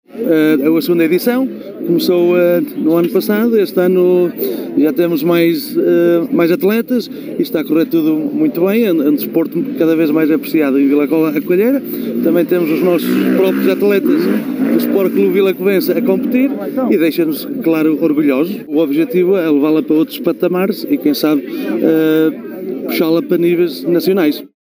Fernando Guedes e Manuel Ferreira, Presidente da Junta e Presidente da Assembleia de Freguesia de Vila Cova à Coelheira